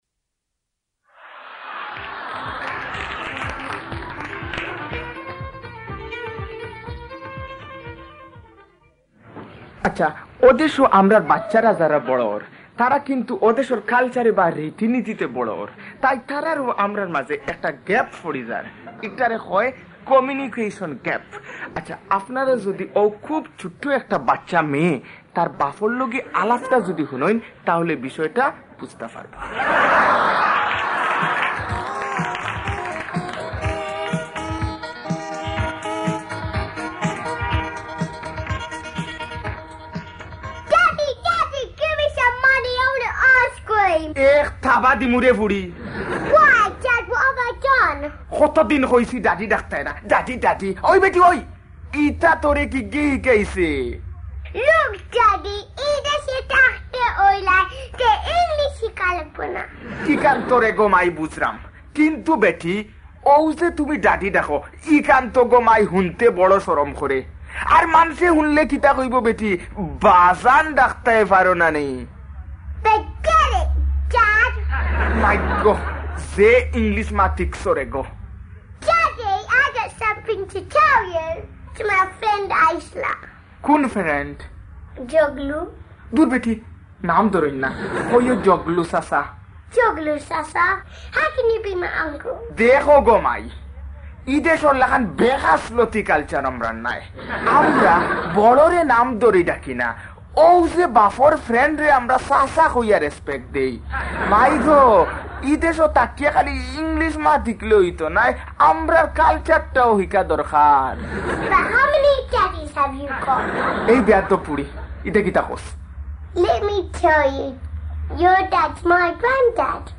Shoriate Part 2 – Comedy